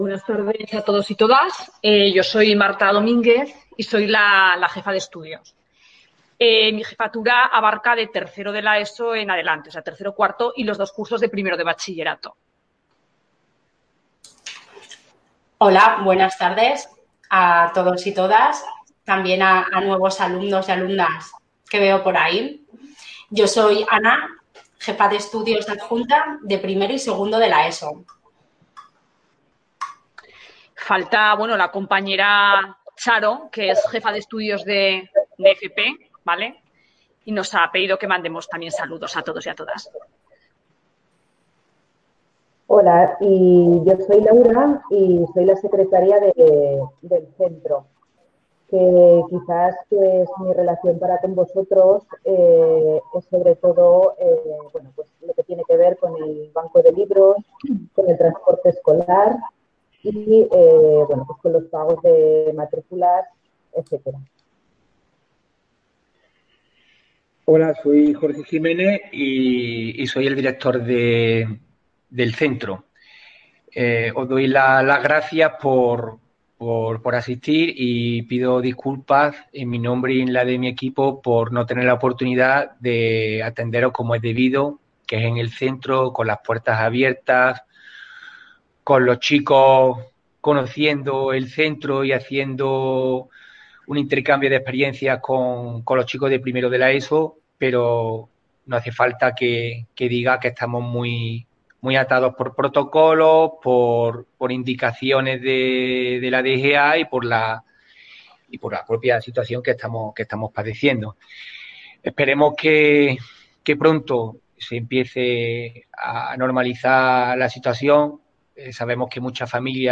Audio puertas abiertas 18 de mayo 2021